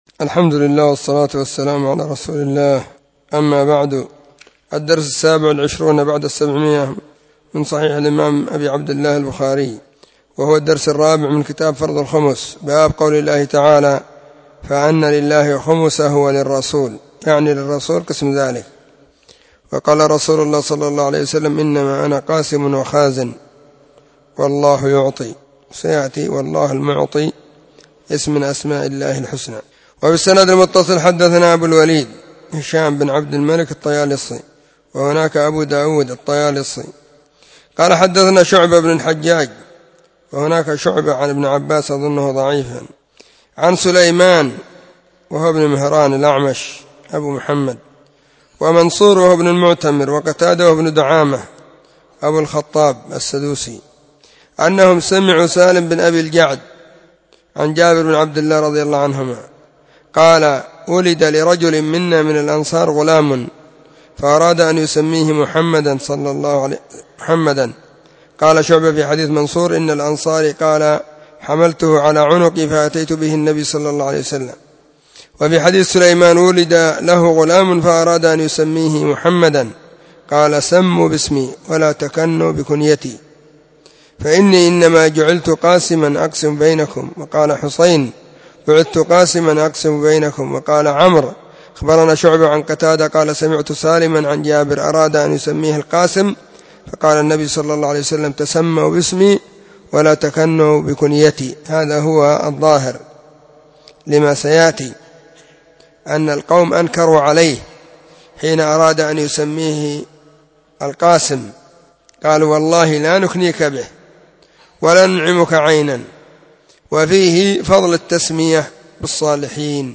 🕐 [بين مغرب وعشاء – الدرس الثاني]
كتاب-فرض-الخمس-الدرس-4-2.mp3